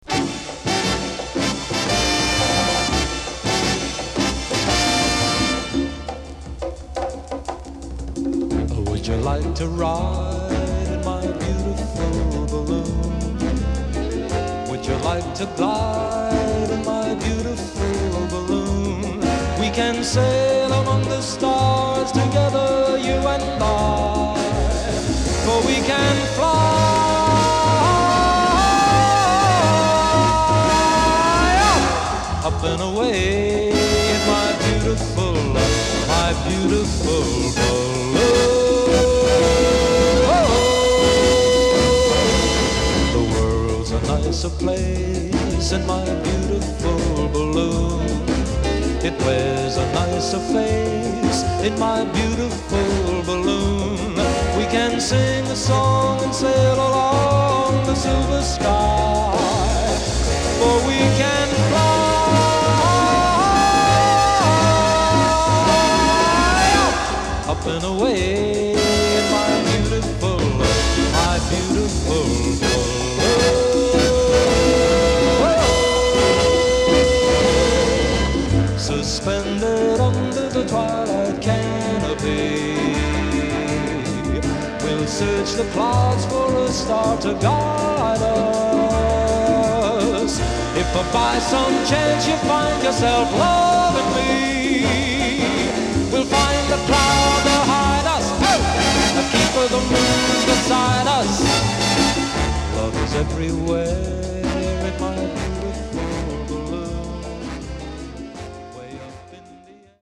ダイナミックなオーケストレーション